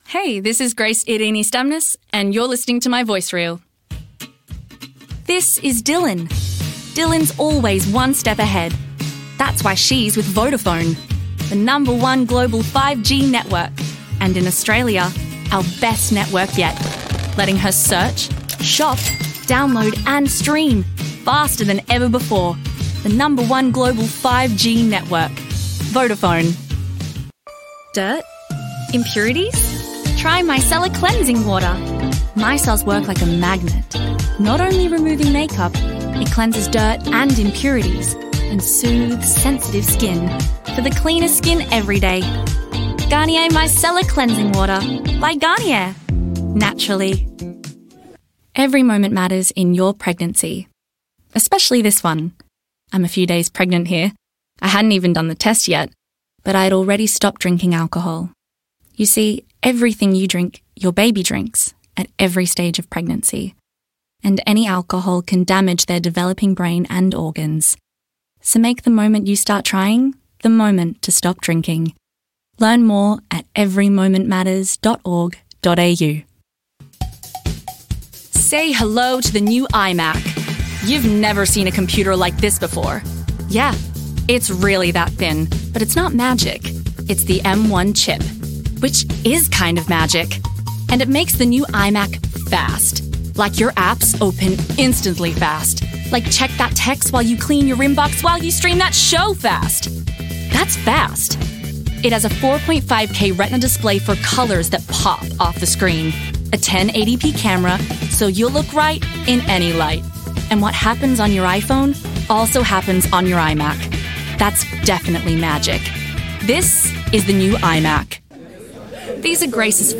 Her vocal range extends from friendly and youthful, to mature and authoritative, with crisp and bright vocal features.